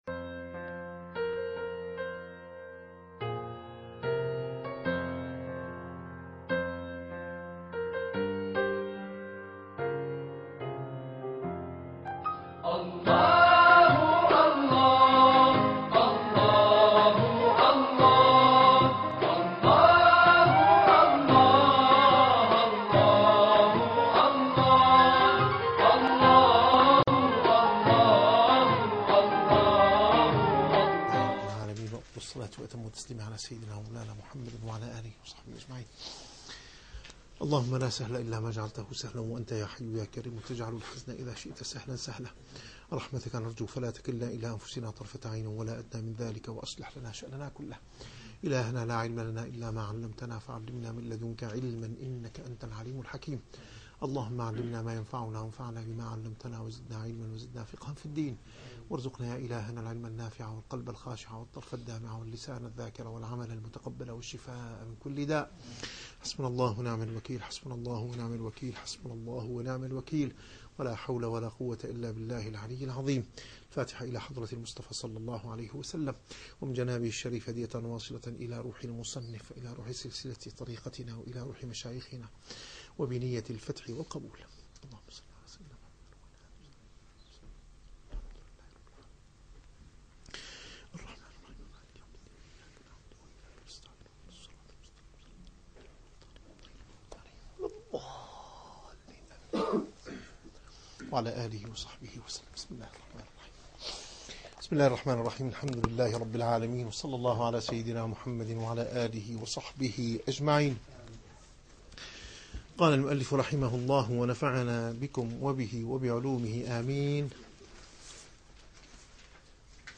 - الدروس العلمية - الرسالة القشيرية - الرسالة القشيرية / الدرس الثامن والأربعون.